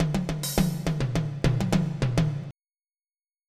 MIDI Music File
tomloops.mp3